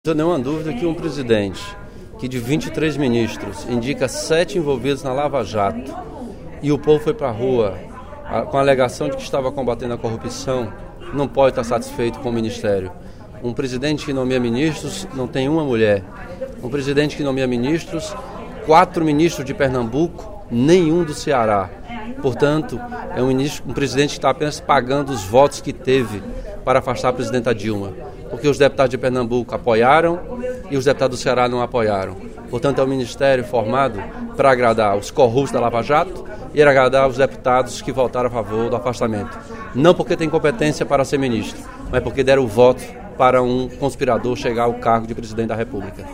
O deputado Elmano Freitas (PT) avaliou, no primeiro expediente da sessão plenária desta terça-feira (17/05), o Governo do presidente interino, Michel Temer. Para o parlamentar, as pessoas foram enganadas e Temer faz parte de um golpe político.